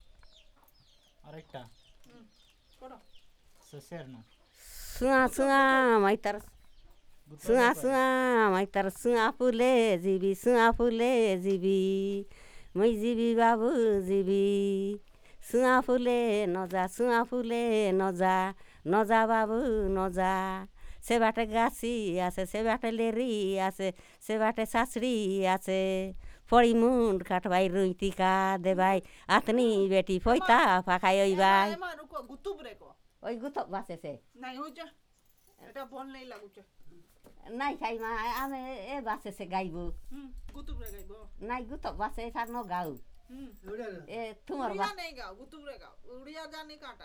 Perfomance of folk song